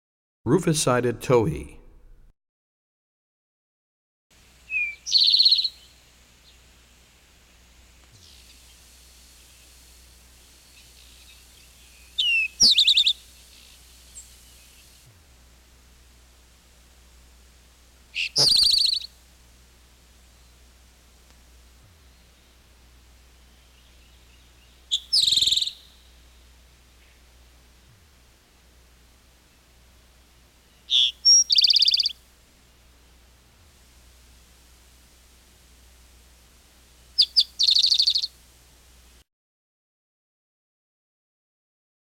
77 Rufous Sided Towhee.mp3